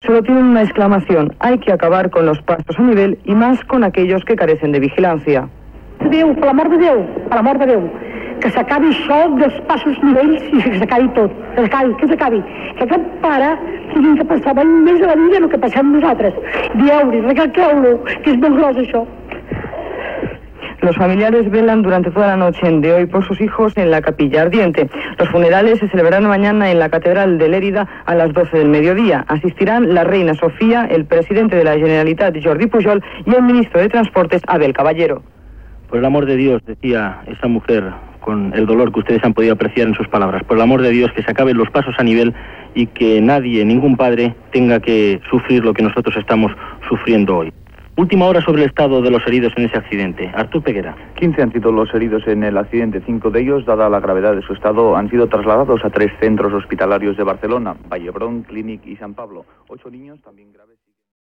Informació del resultat de l'accident d'un tren i un autocar a Juneda. Declaracions d'una afectada i estat dels ferits
Informatiu